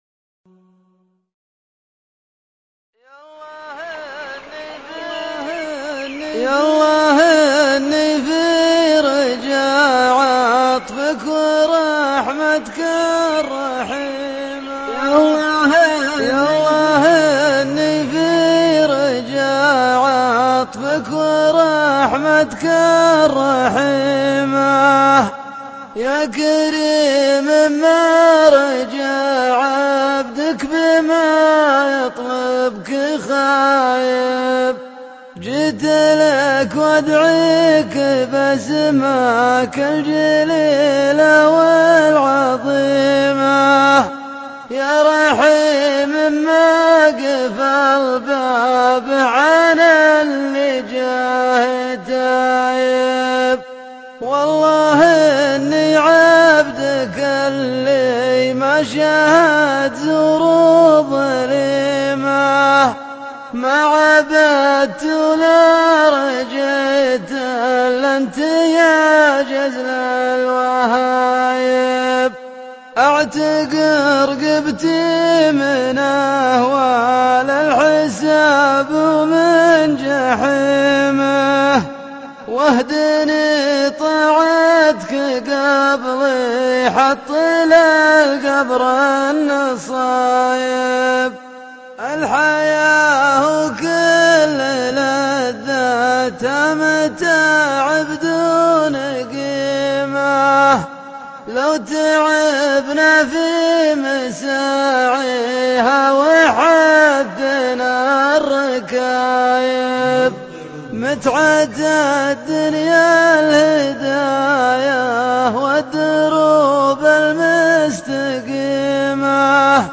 شيله   02 سبتمبر 2011